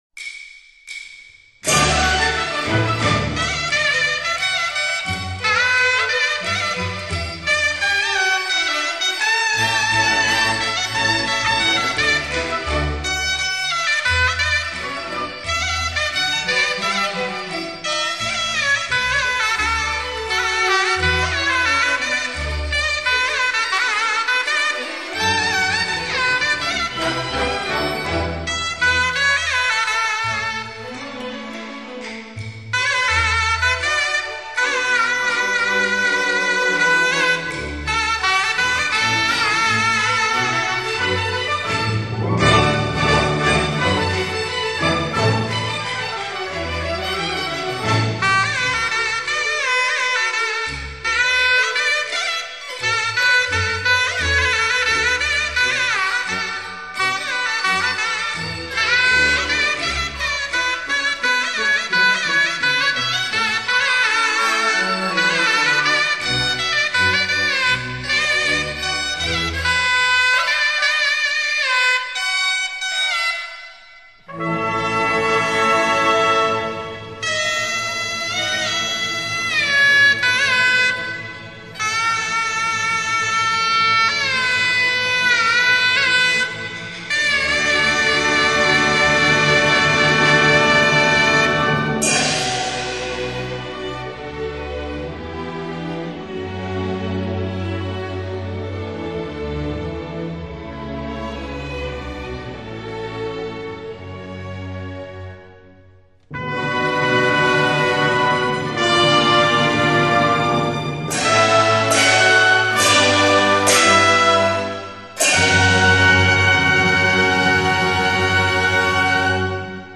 现代京剧交响组曲